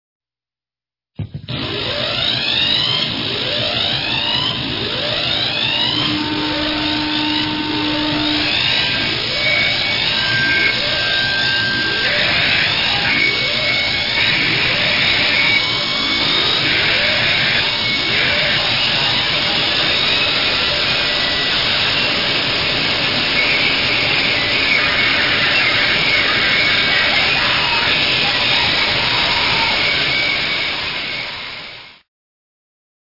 パワーエレクトロニクスの極地！